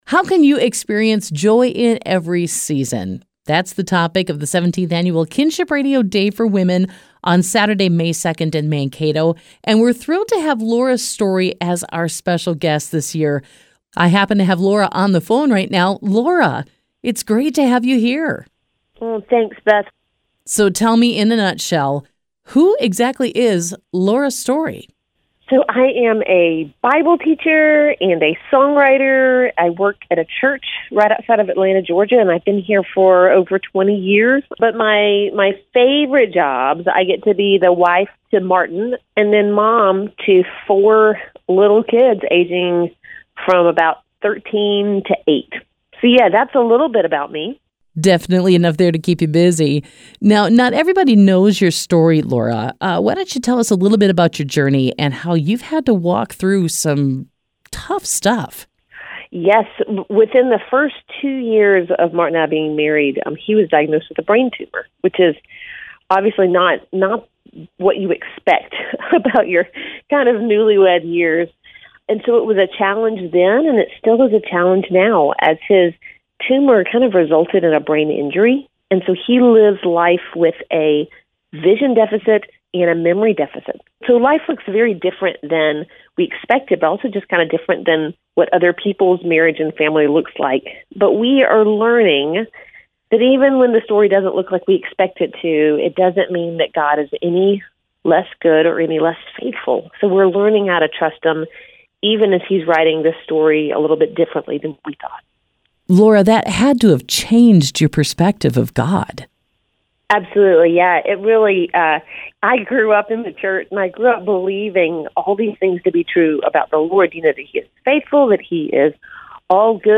An Interview with Laura Story and Seeing God’s Faithfulness
Laura-Story-Interview-short-FINAL.mp3